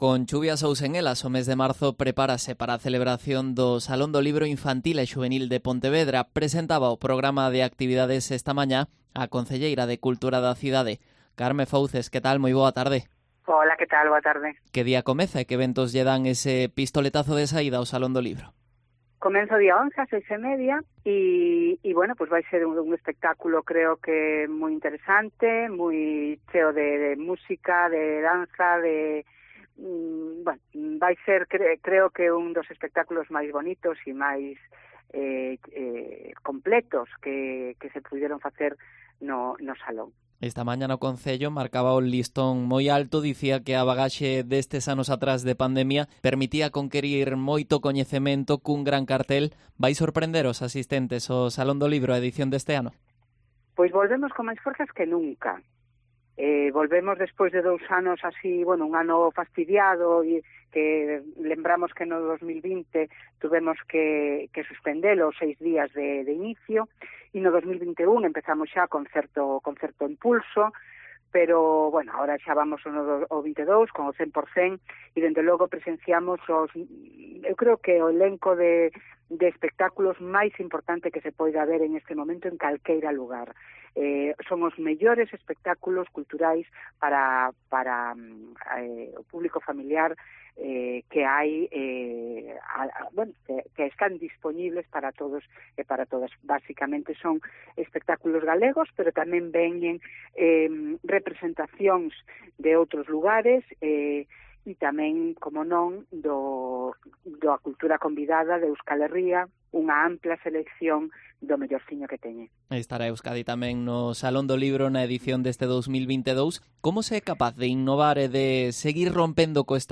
Entrevista Carme Fouces, concelleira de Cultura en Pontevedra